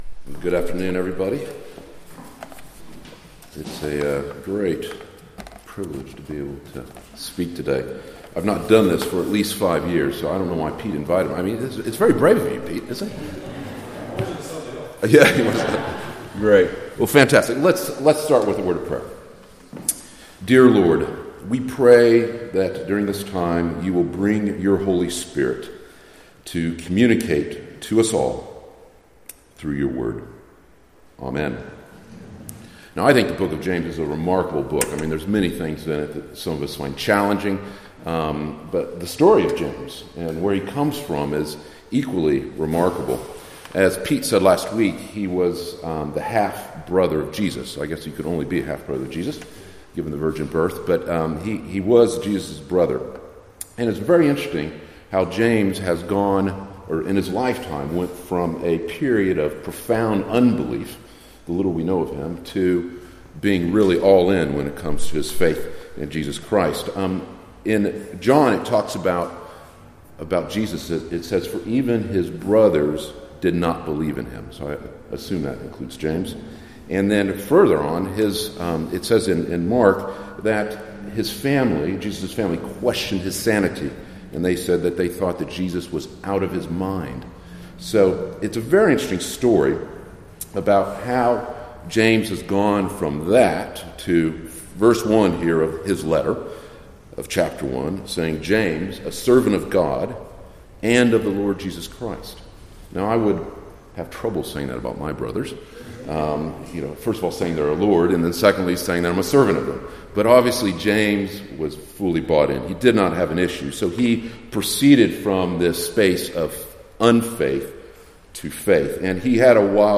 James 9-16 Service Type: Weekly Service at 4pm Bible Text